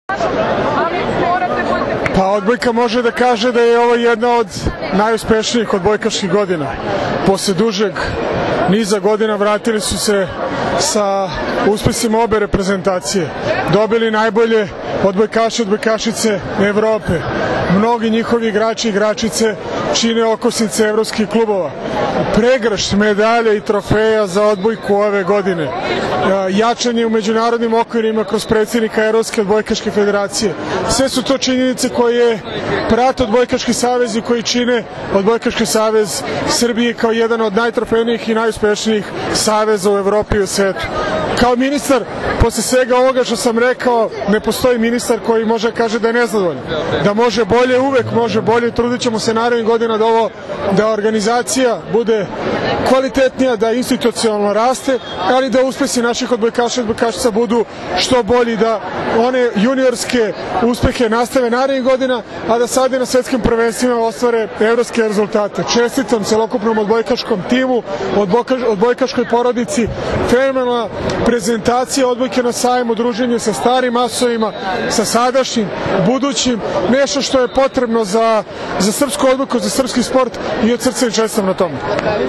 6. MEĐUNARODNI SAJAM SPORTA 2017. – “SRBIJA ZEMLJA SPORTA”
IZJAVA VANJE UDOVIČIĆA